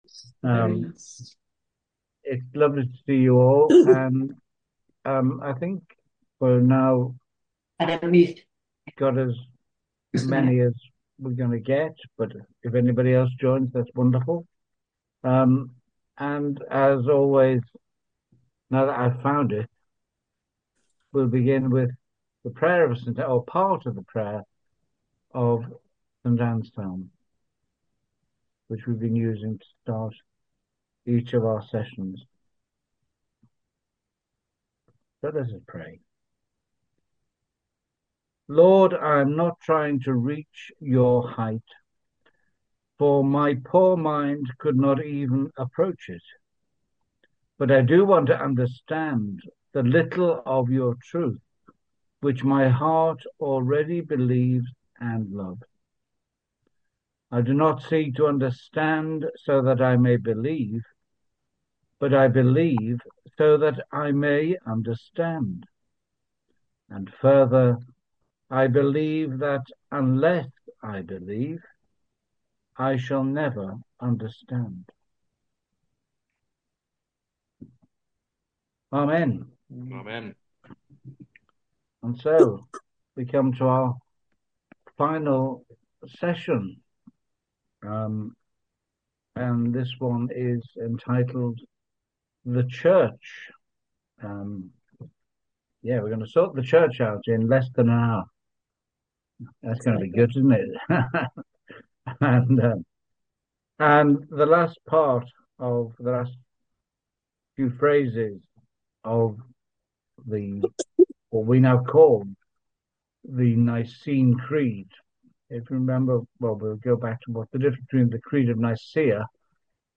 Welcome to the recordings of our Zoom lent course for 2025, open to anyone who would like to attend